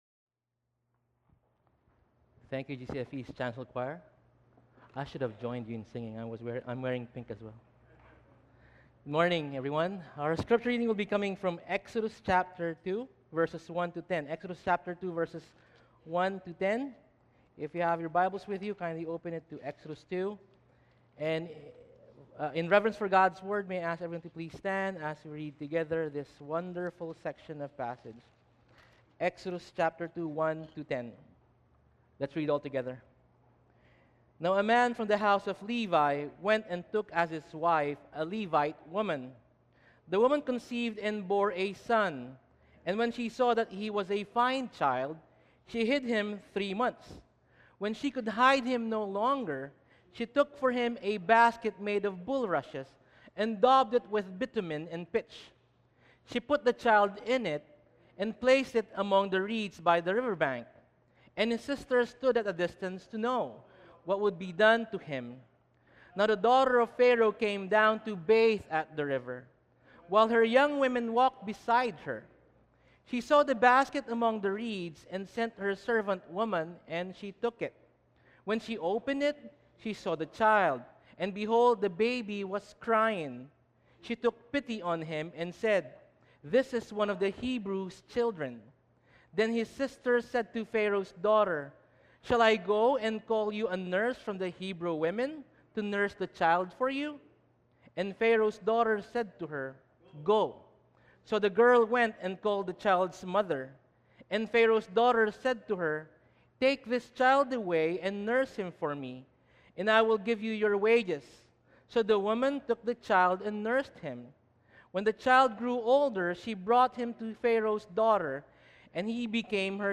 Exodus 2:1-10 Service: English Topics: Mother's Day « Lord’s Supper